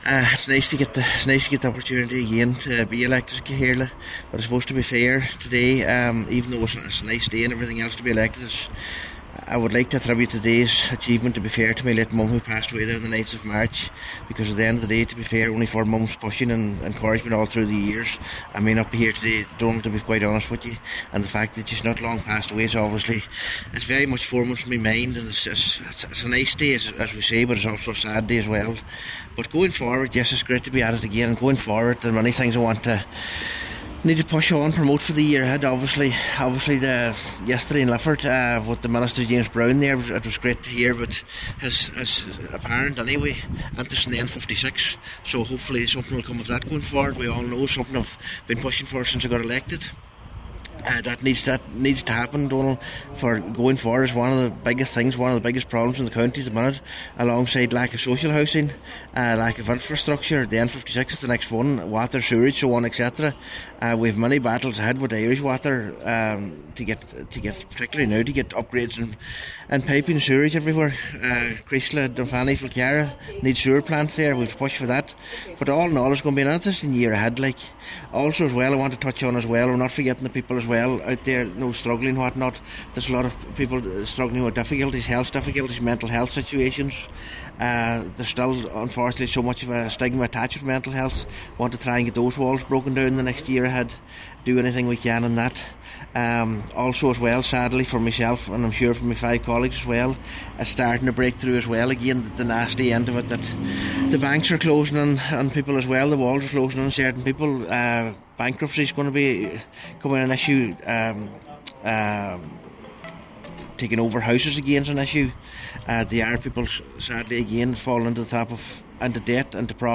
Cllr McClafferty says following the death of his mother earlier this year, this is a bittersweet day: